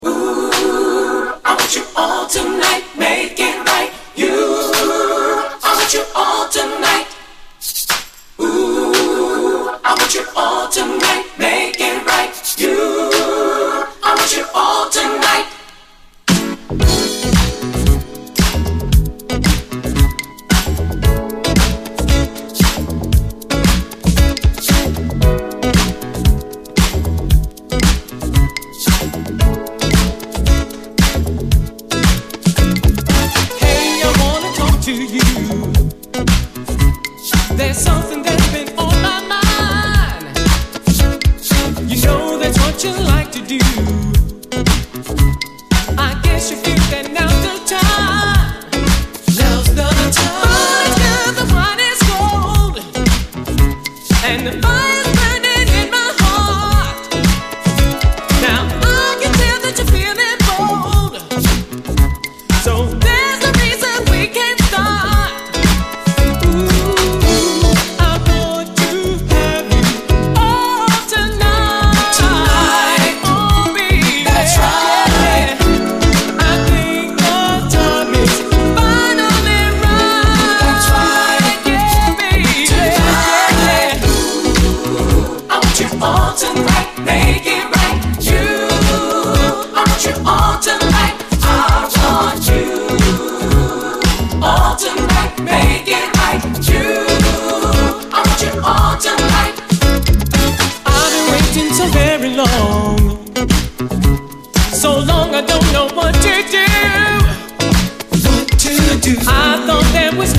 SOUL, 70's～ SOUL, DISCO
艶かしくアーバンな80’Sモダン・ブギー〜ガラージ・クラシック！